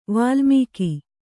♪ vālmīki